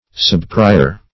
Search Result for " subprior" : The Collaborative International Dictionary of English v.0.48: Subprior \Sub*pri"or\, n. [Pref. sub + prior: cf. F. sous-prieur.]